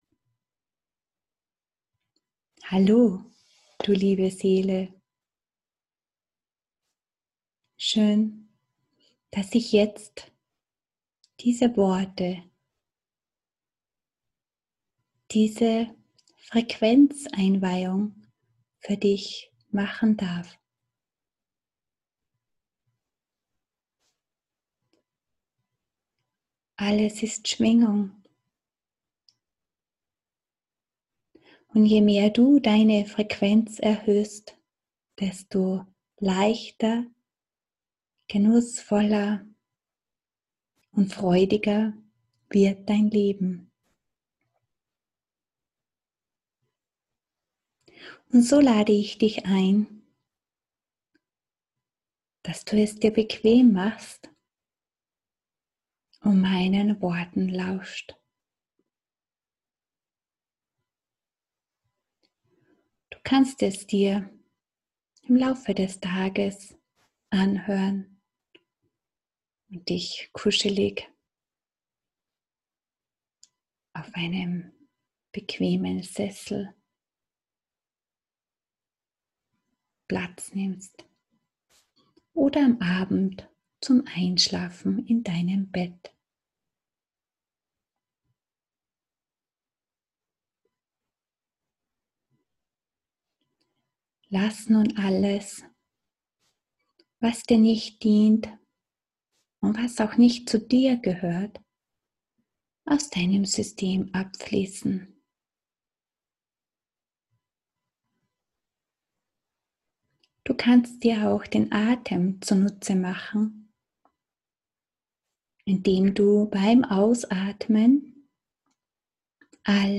Du kannst dir jetzt deine kostenfreie Meditation runterladen
Freebie-Meditation-Verbindung-mit-deinem-Wahren-Sein.mp3